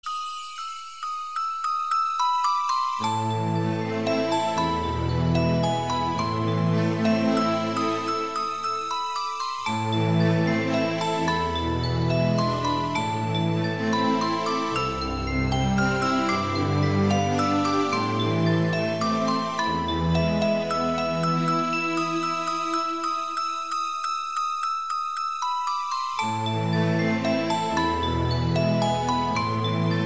Kategorien: Klassische